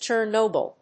音節Cher・no・byl 発音記号・読み方
/tʃɚnóʊbl(米国英語), tʃəːnˈəʊ‐(英国英語)/